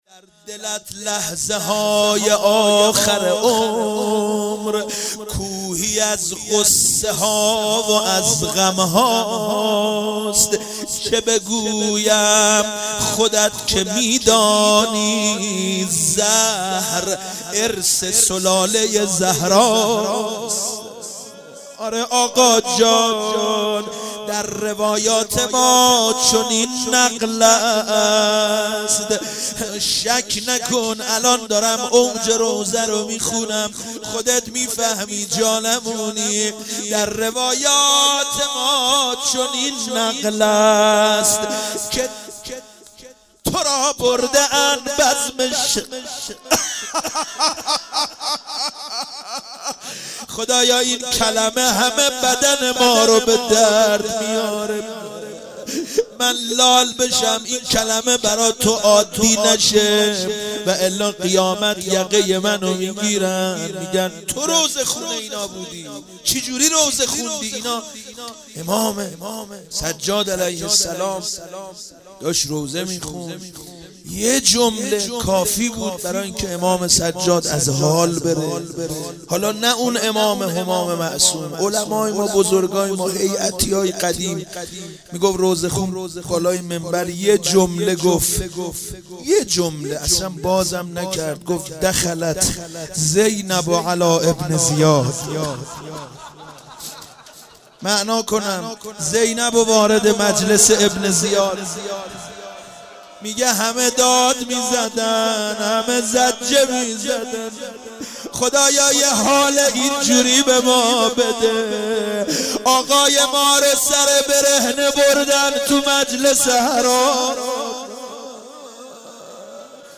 شب پنجم رمضان95، حاج محمدرضا طاهری